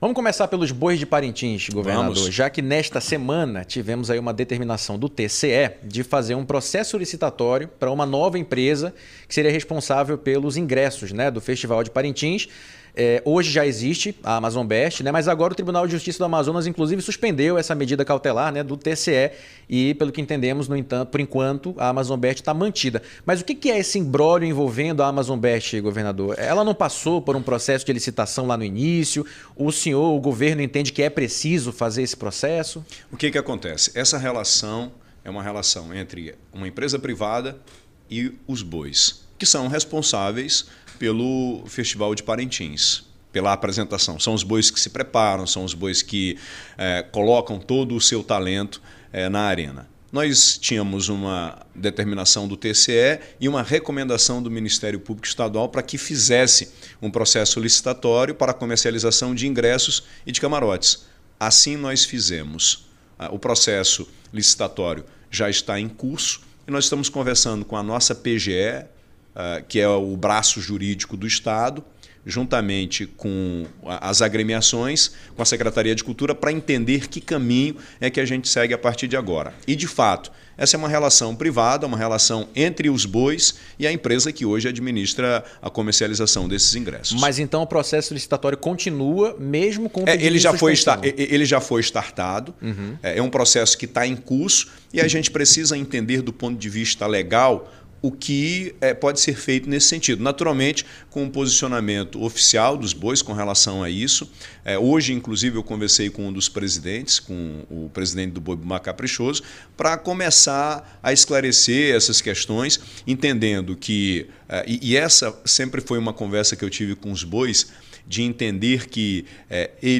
Justiça do Amazonas suspendeu medida que previa mudanças na gestão da venda de ingressos do Festival Folclórico de Parintins. Governador Wilson Lima falou sobre o assunto durante entrevista na manhã desta quinta (19) no CBN Jornal da Manhã.
EMTREVISTA-WILSON-LIMA_PARINTINS.mp3